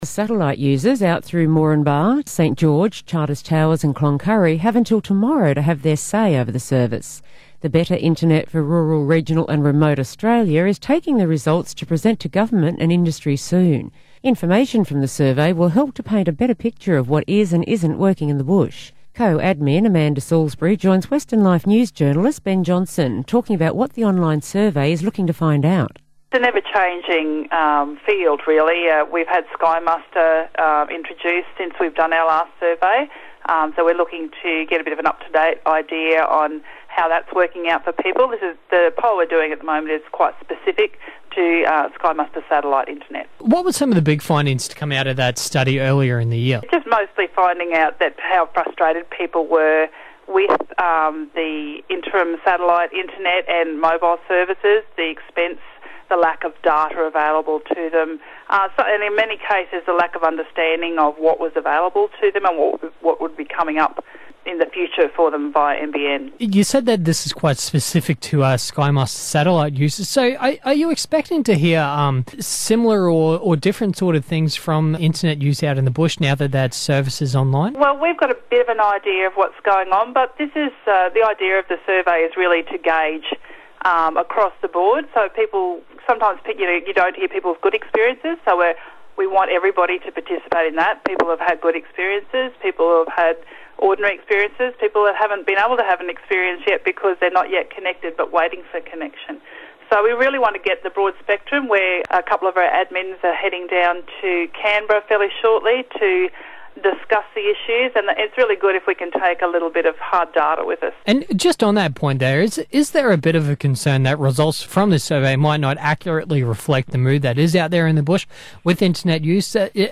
Interviews on Austereo (SkyMuster Survey). (Sept 2016)